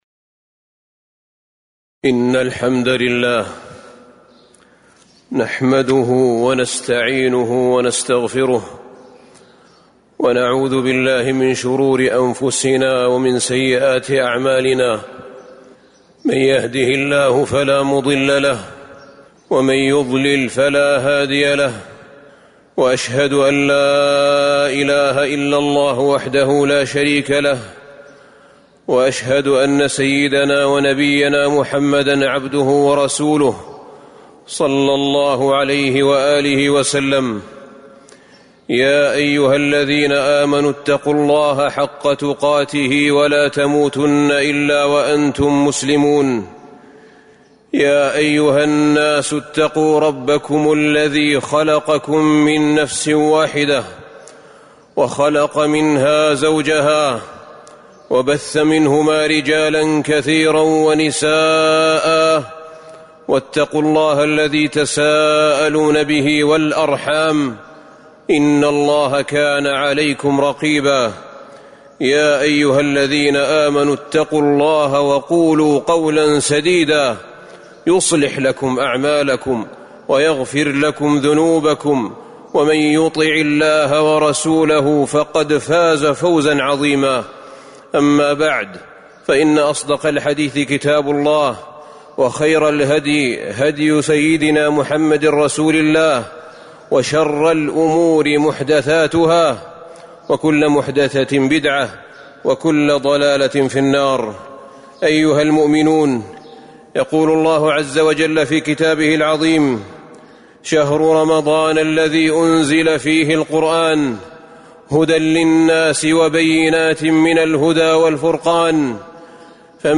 تاريخ النشر ٢٢ شعبان ١٤٤٣ هـ المكان: المسجد النبوي الشيخ: فضيلة الشيخ أحمد بن طالب بن حميد فضيلة الشيخ أحمد بن طالب بن حميد الاستعداد لرمضان